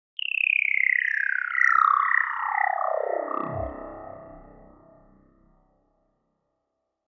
Sci-Fi Descending Electronic Sound Effect
Description: Sci-fi descending electronic sound effect. Create futuristic high-pitched descending or slowing tones that fit perfectly in games, videos, or reels with high-tech and space themes. Use them for spaceship, UFO, or vehicle drop sounds, smooth transitions, and dynamic swoosh effects.
Sci-fi-descending-electronic-sound-effect.mp3